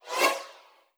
Modern UI SFX / SlidesAndTransitions
SwooshSlide4.wav